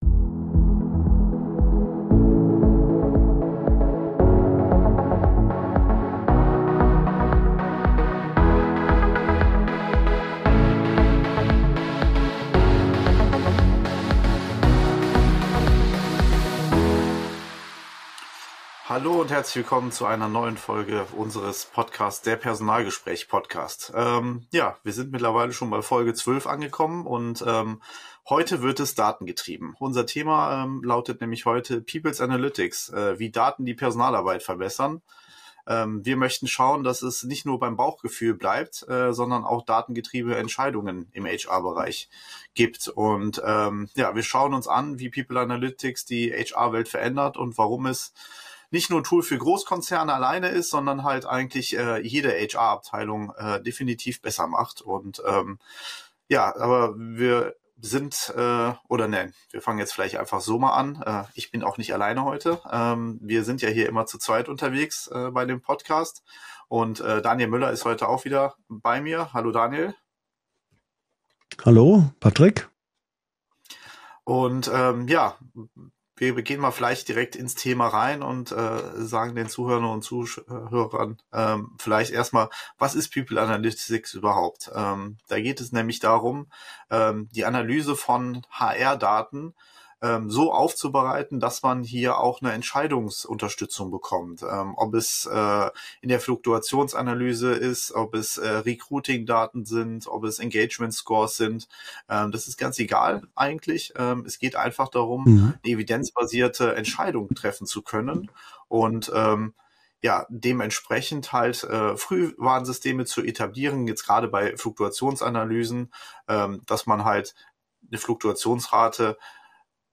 Der Personalgespräch Podcast – Zwei Profis, ein Team.